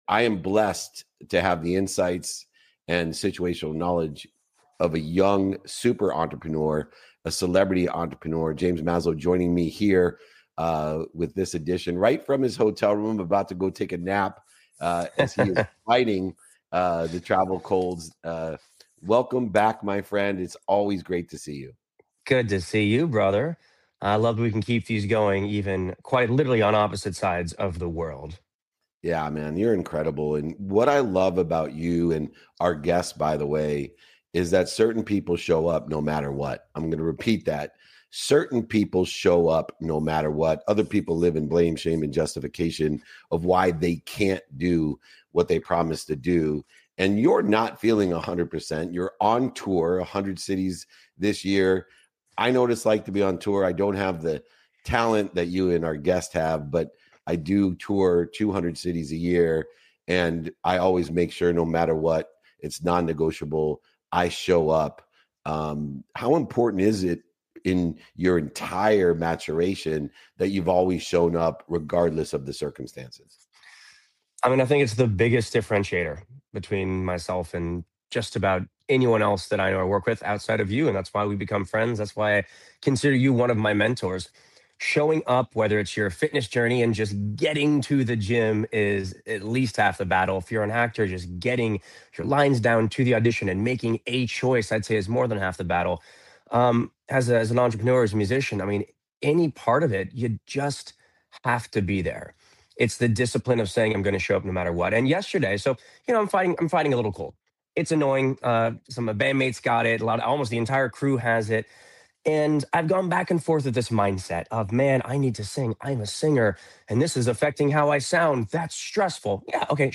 In today’s episode, I’m joined by James Maslow and Justin Guarini for an honest conversation about showing up when conditions are far from perfect.